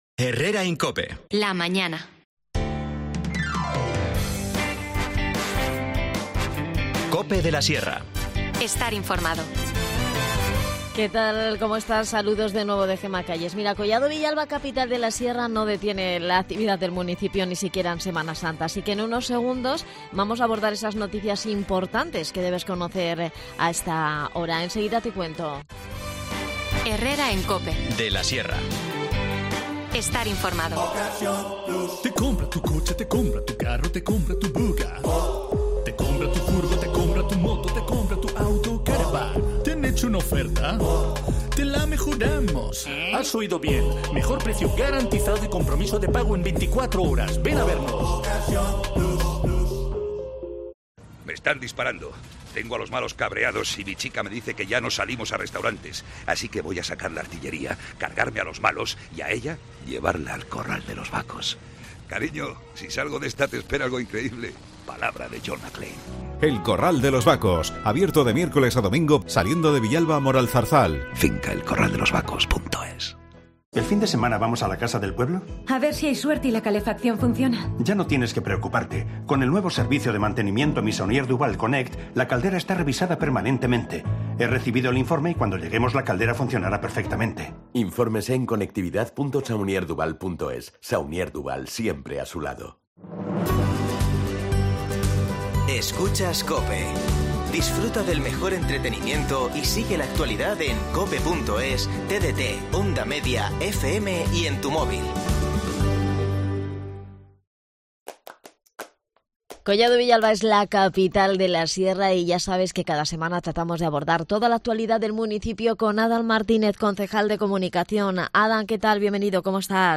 INFORMACIÓN LOCAL
Hablamos con Adan Martínez, concejal de Comunicación sobre esta y otras noticias del municipio relacionadas con el ocio, la salud y las procesiones de Semana Santa en Collado Villalba.